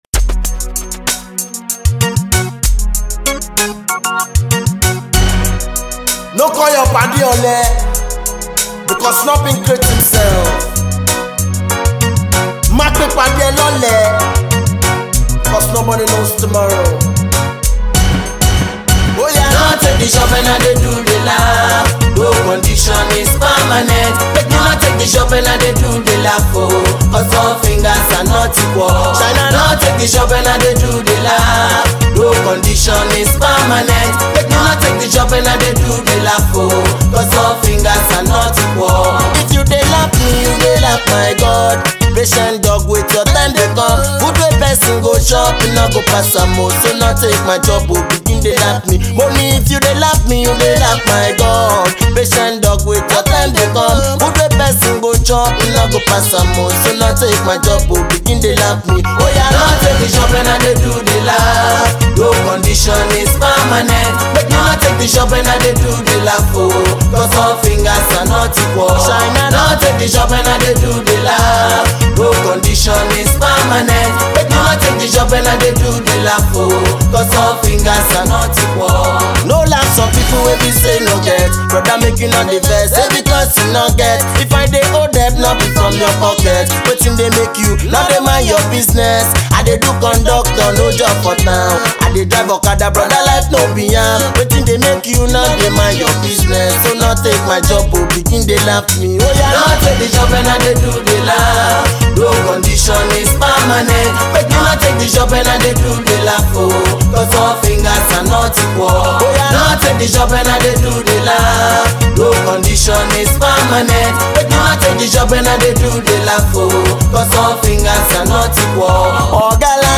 galala and konto